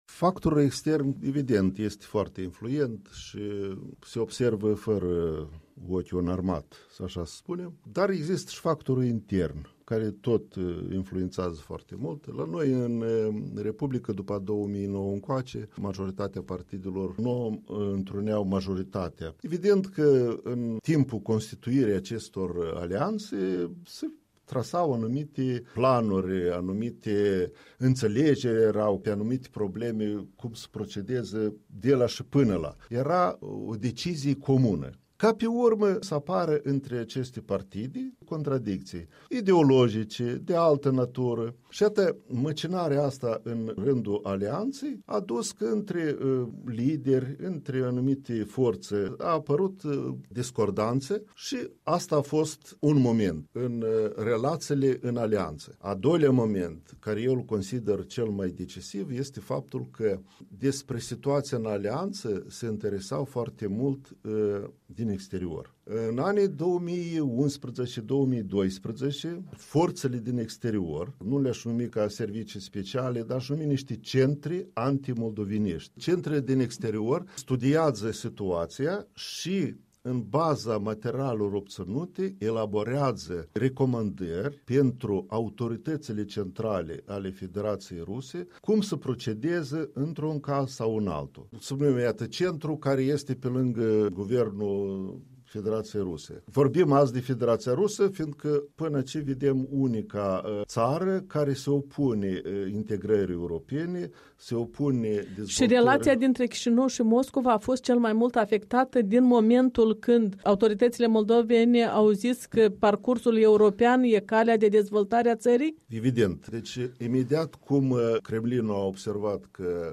Interviu cu Valentin Dediu